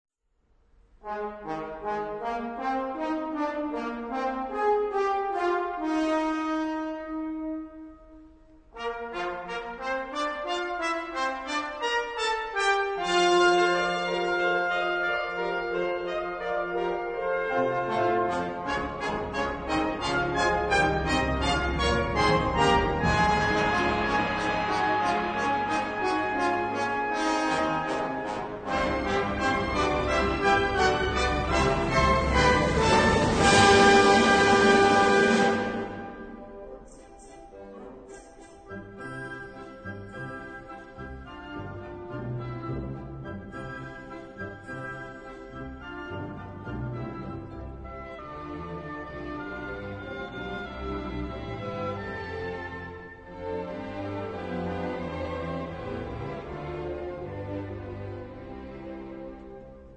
music: Traditional
key: A flat Major